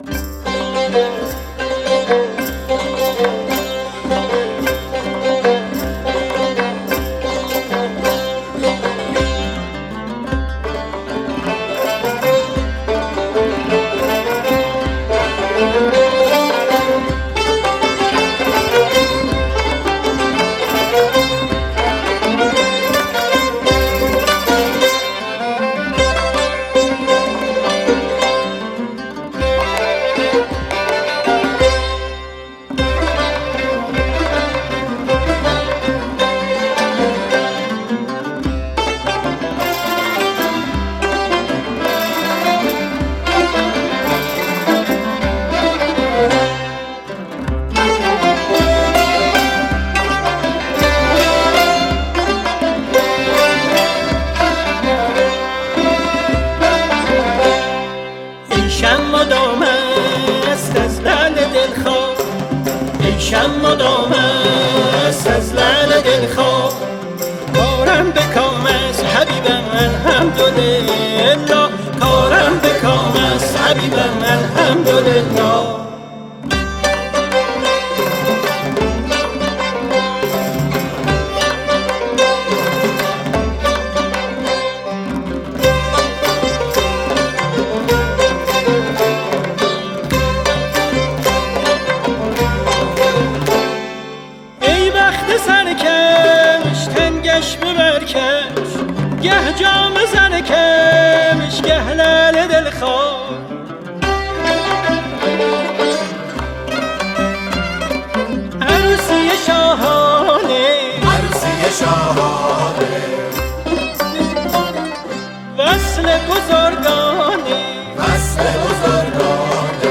تصنیف